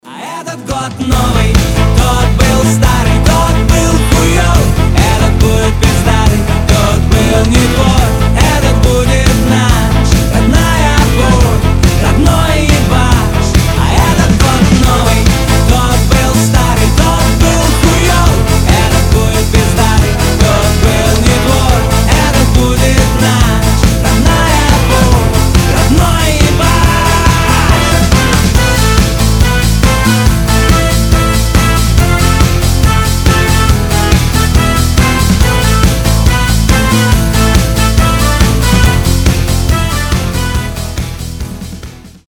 • Качество: 320, Stereo
позитивные
веселые
труба
ска